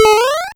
SmallJump.wav